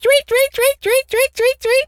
bird_tweety_tweet_02.wav